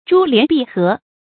珠連璧合 注音： ㄓㄨ ㄌㄧㄢˊ ㄅㄧˋ ㄏㄜˊ 讀音讀法： 意思解釋： ①指日月、五星同時出現于天的一方。